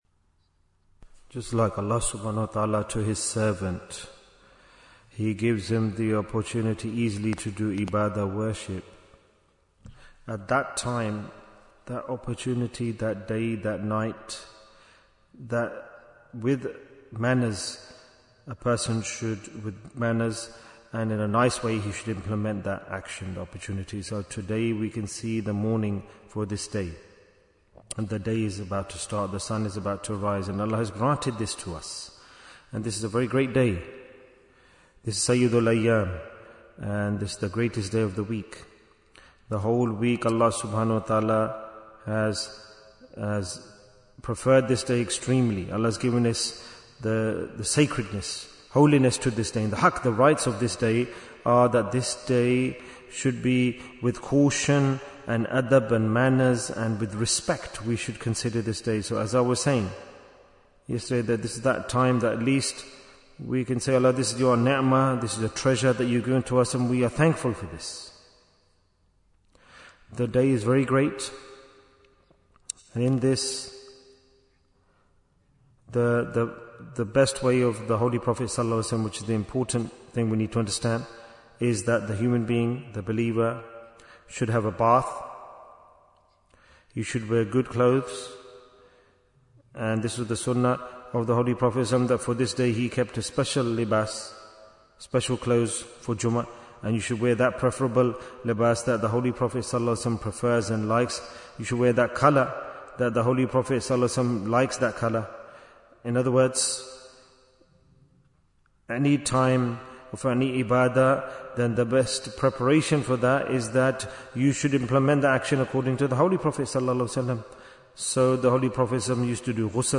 Why is Tazkiyyah Important? - Part 25 Bayan, 18 minutes3rd April, 2026